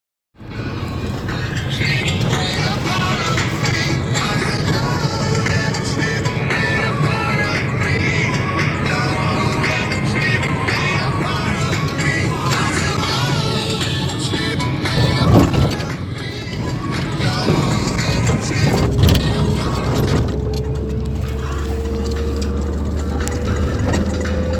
Извиняюсь за качество (записано в маршрутке).